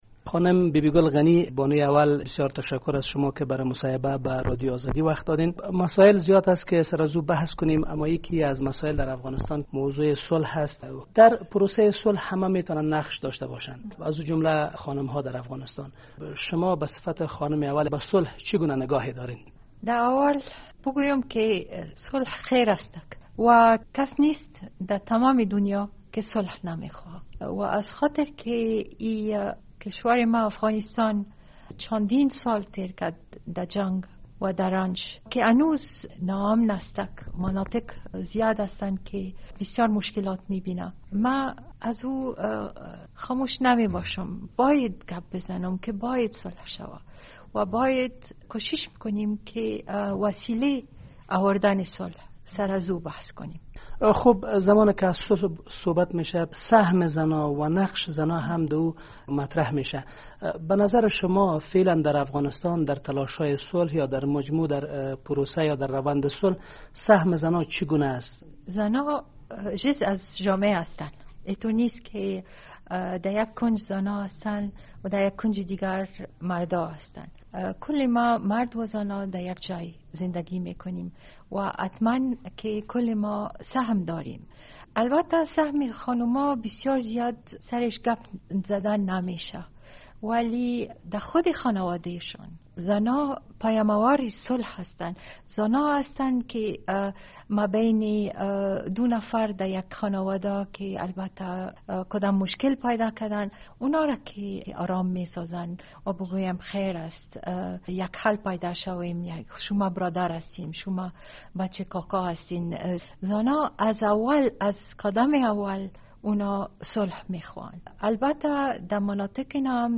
خانم غنی روز سه شنبه در یک مصاحبه اختصاصی با رادیو آزادی گفت که جنگ جز ویرانی نتیجه دیگری ندارد ...